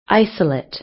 Watch out! You may see this word with another pronunciation and its meaning is then different.